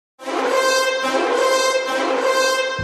kaamelott-corne-ringtone.mp3